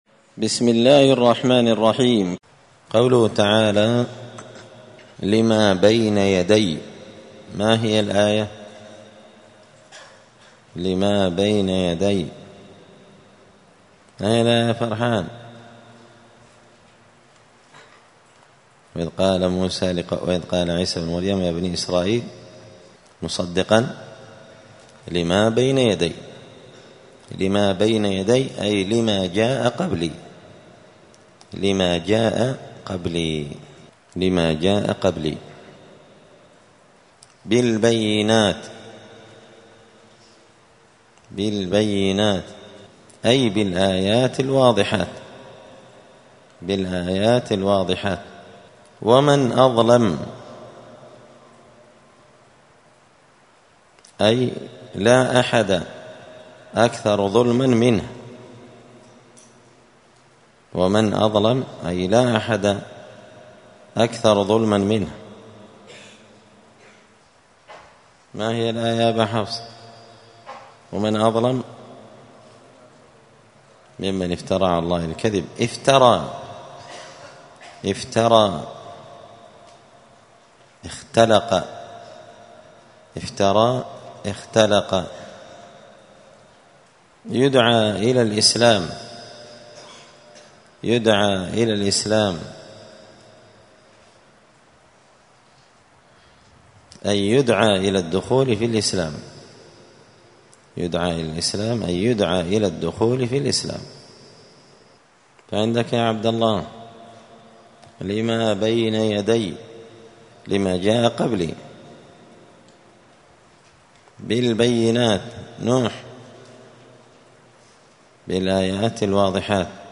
*(جزء المجادلة سورة الصف الدرس 140)*